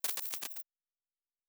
Sci-Fi Sounds / Electric / Glitch 3_02.wav
Glitch 3_02.wav